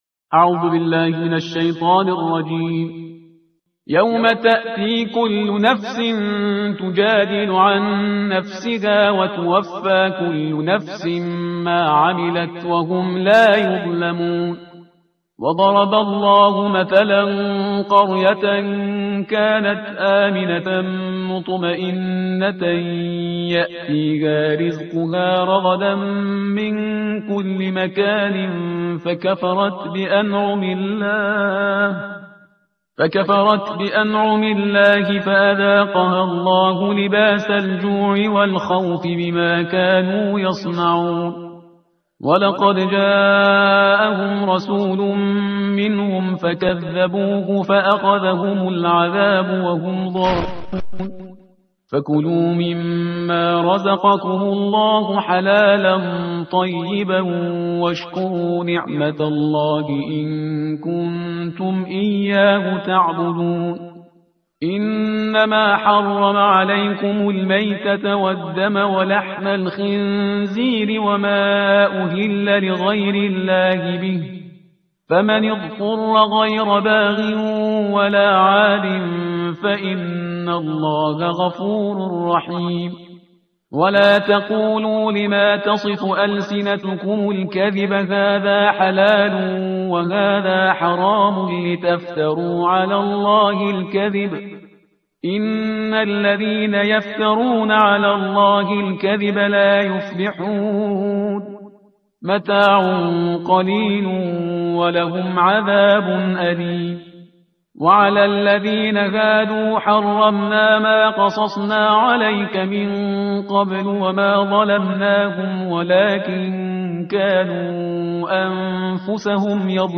ترتیل صفحه 280 قرآن – جزء چهاردهم